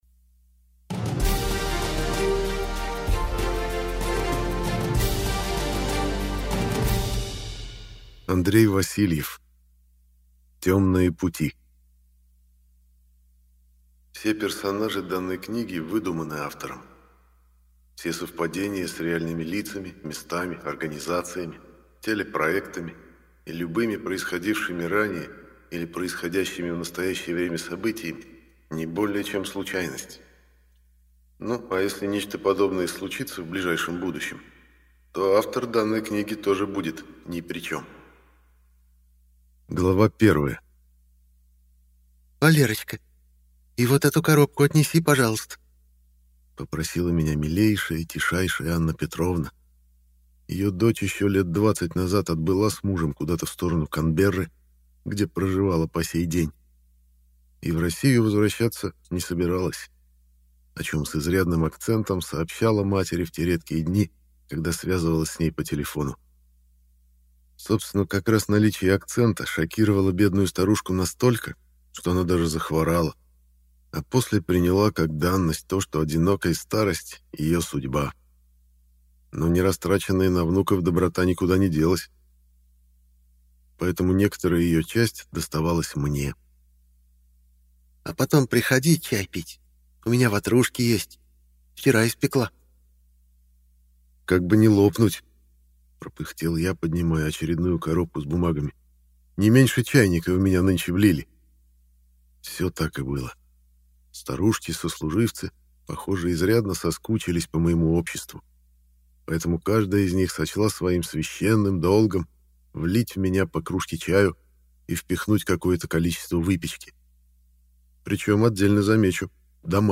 Аудиокнига Тёмные пути - купить, скачать и слушать онлайн | КнигоПоиск